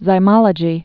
(zī-mŏlə-jē)